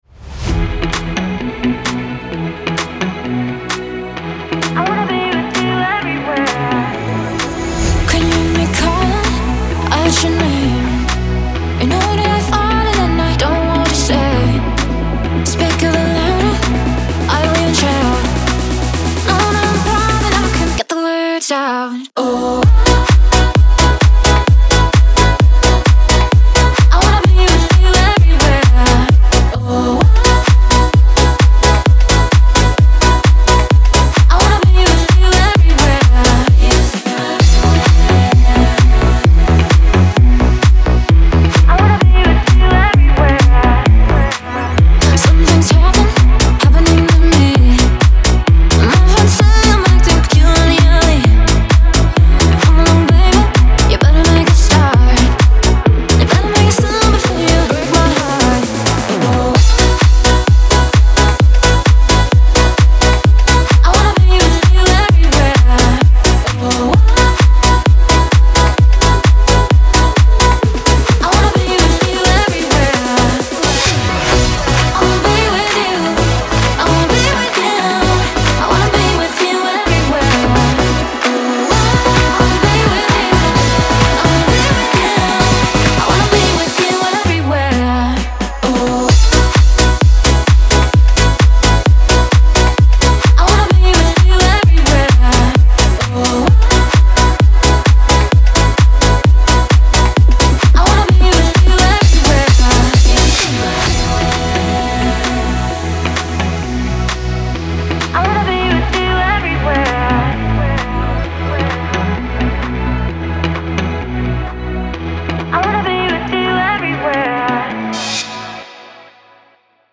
pop , танцы
dance